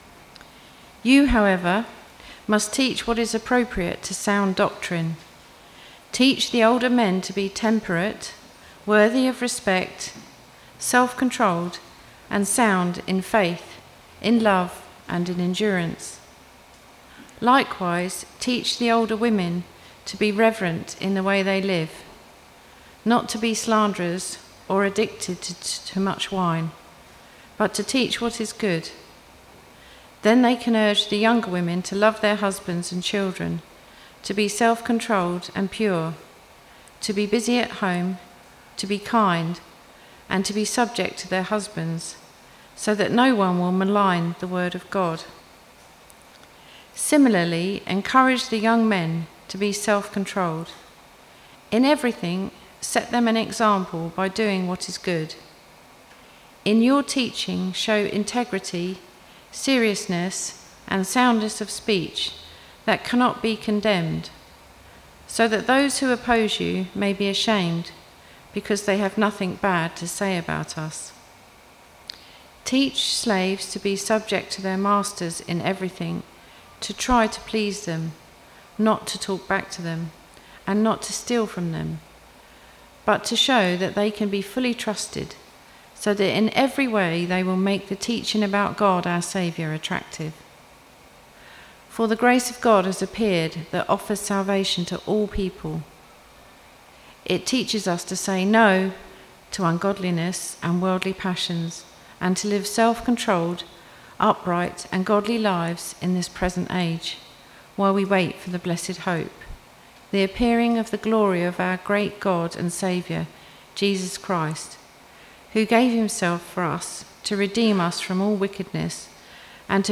Media for Christ Church Morning Service on Sun 13th Jul 2025 10:30
Sermon